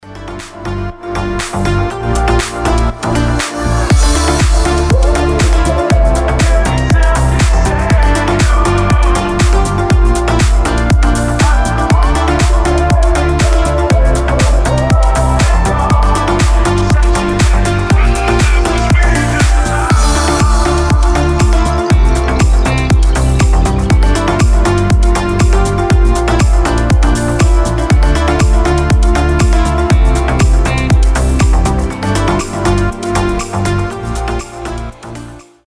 • Качество: 320, Stereo
красивые
deep house
мелодичные
восточные